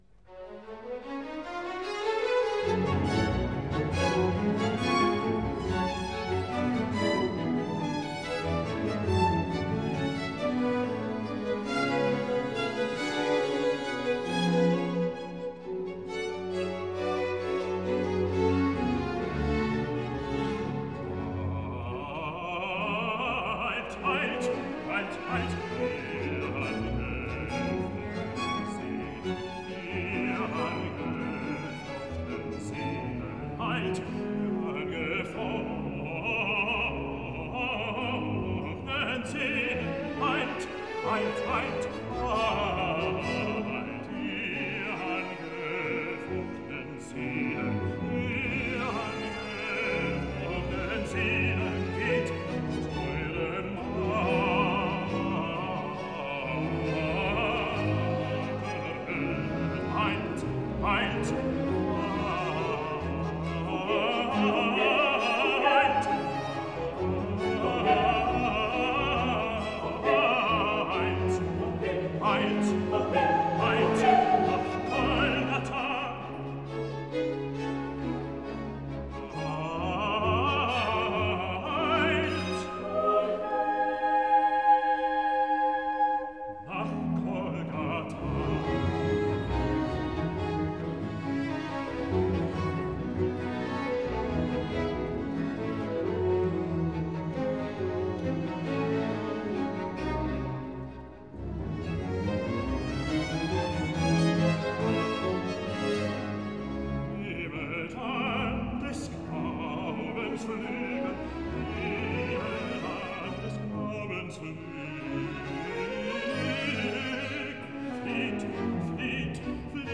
10 Aria Eilt, Ihr Angefochtnen Seelen